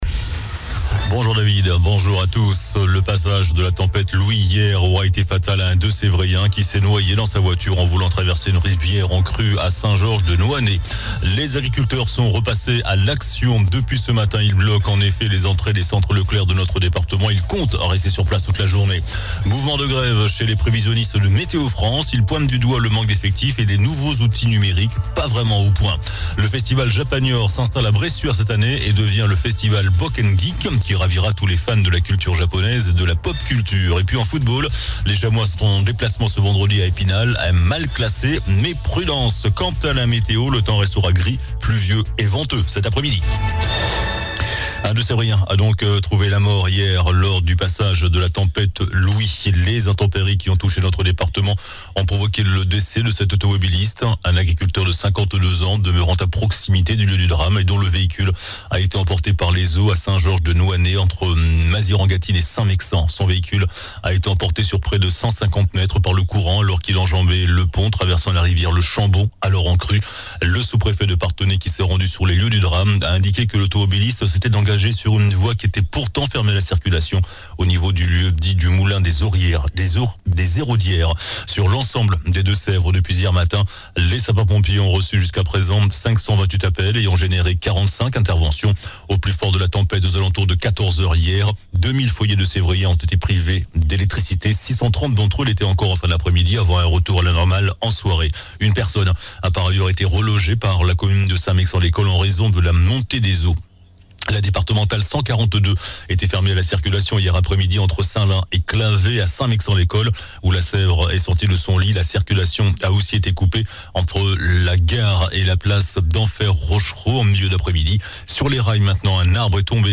JOURNAL DU VENDREDI 23 FEVRIER ( MIDI )